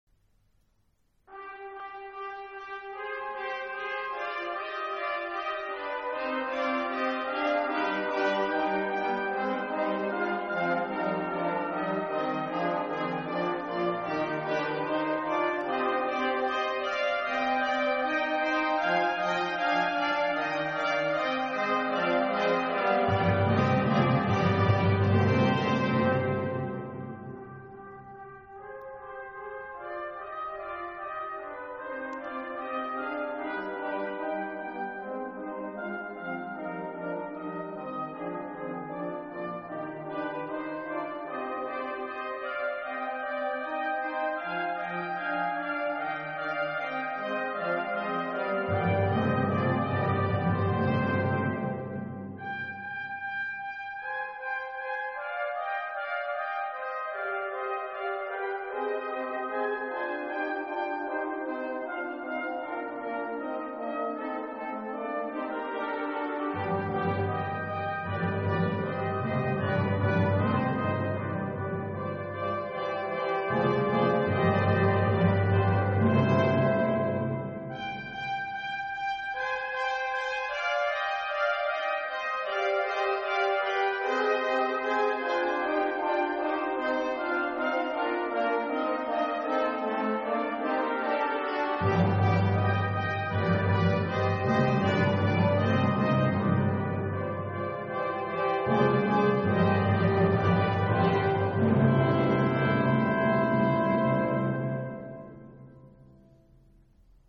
Choeur, cuivres et timbale.
Cette oeuvre se compose de 7 mouvements alternant les pièces vocales et instrumentales (2 trompettes, 2 trombones, 1 timbale).